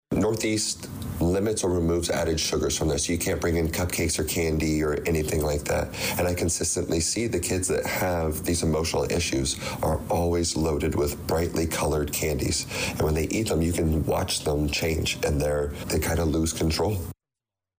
The Danville District 118 Board meeting Wednesday evening (April 23rd) may have been focused on bus issues, along with superintendent thoughts in the backs of many minds.